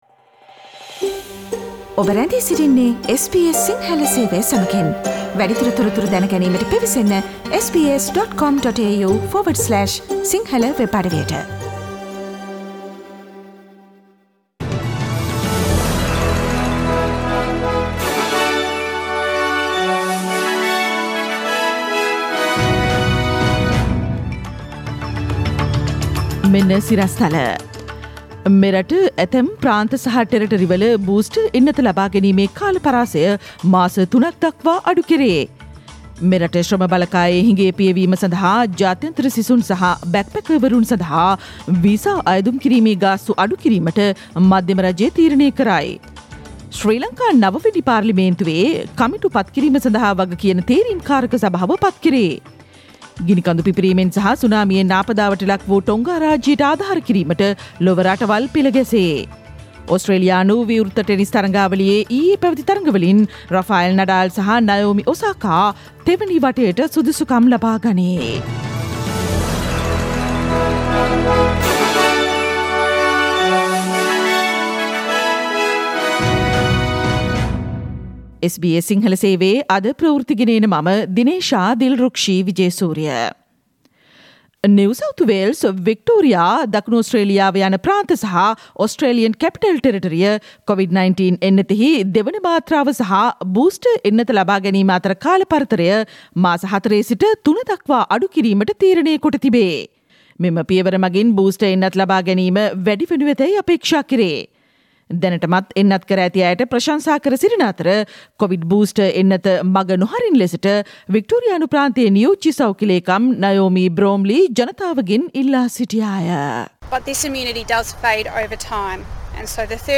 ජනවාරි 20 වන අඟහරුවාදා SBS සිංහල ගුවන්විදුලි වැඩසටහනේ ප්‍රවෘත්ති ප්‍රකාශයට සවන්දෙන්න ඉහත චායාරූපය මත ඇති speaker සලකුණ මත click කරන්න